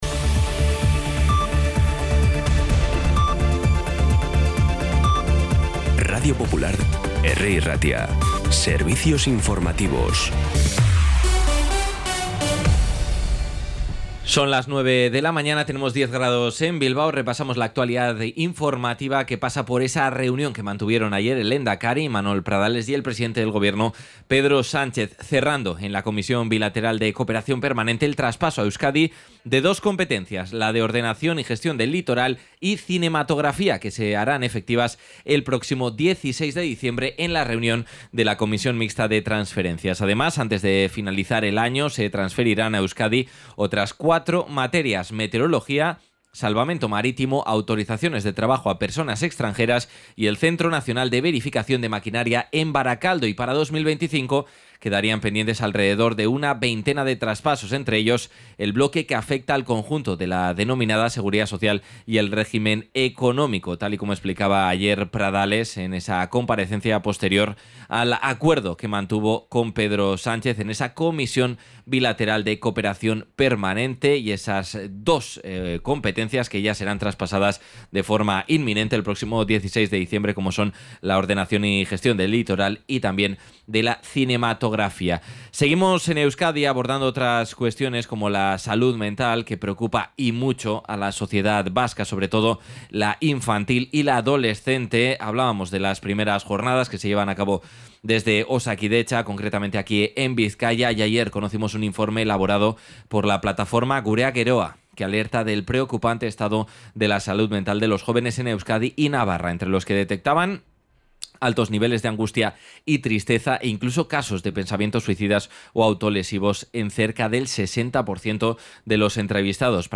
Las noticias de Bilbao y Bizkaia del 28 de noviembre a las 9